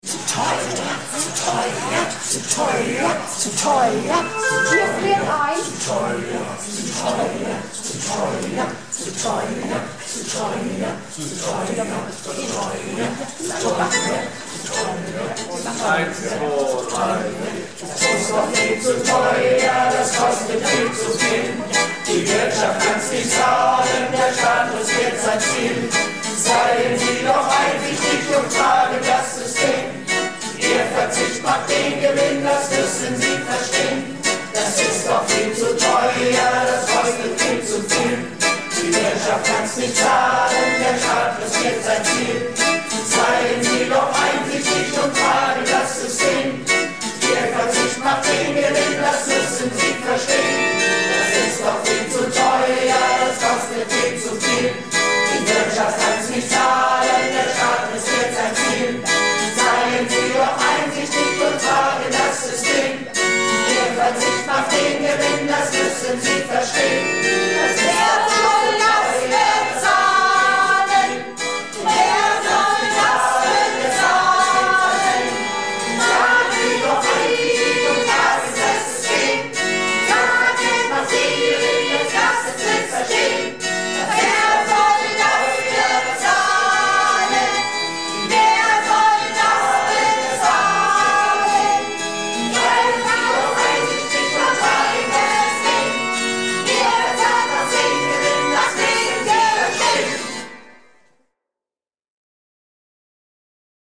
Projektchor "Keine Wahl ist keine Wahl" - Probe am 28.08.19